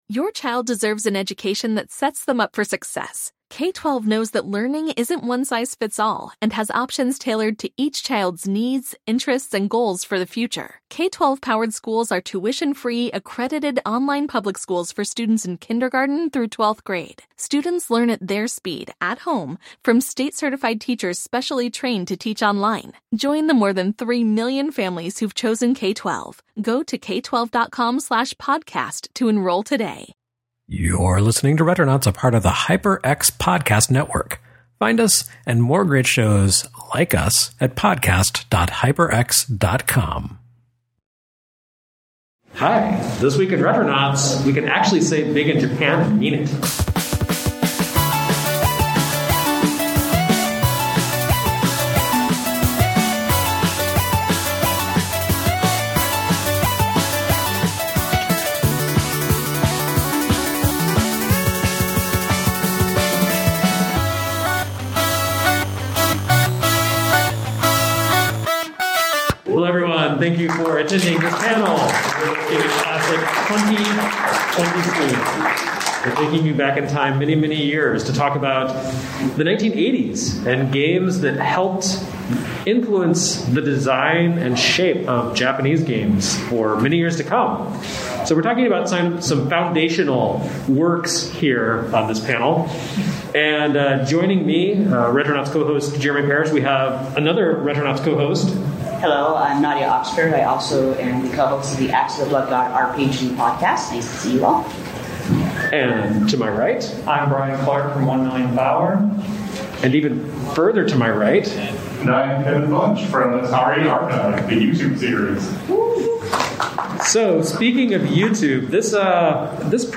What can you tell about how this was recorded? Live from MGC!